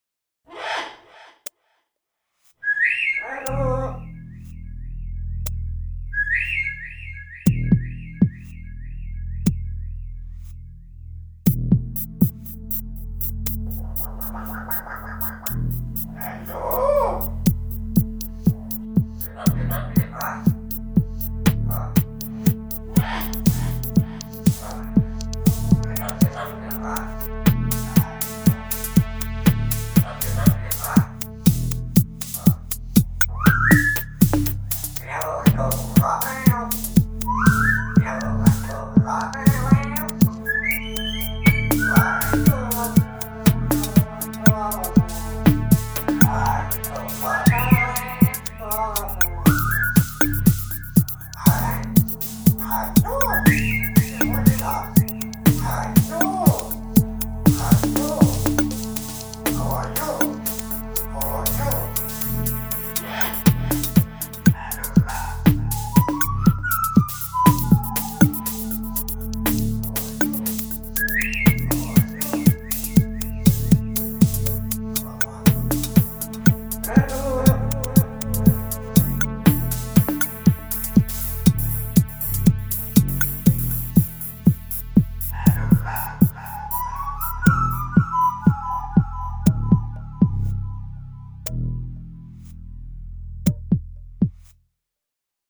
Minimal beat tracks